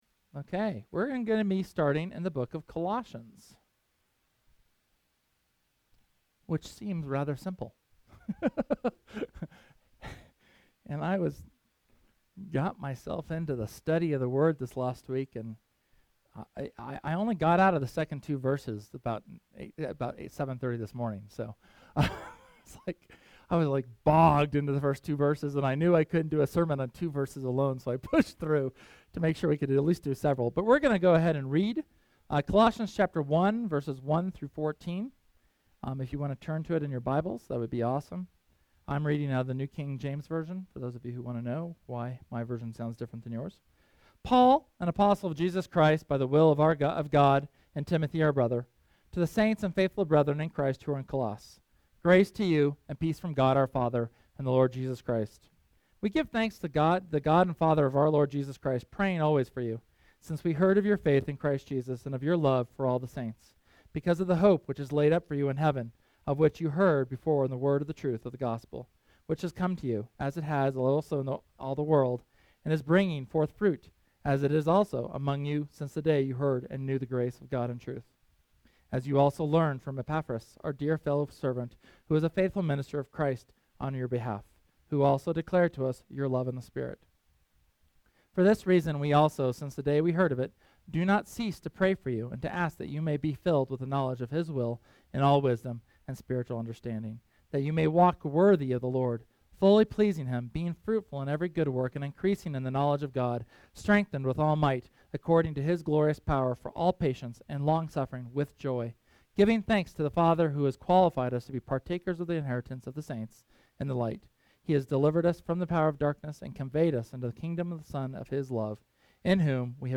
SERMON: Greetings from Paul